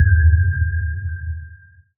sonarTailSuitVeryClose1.ogg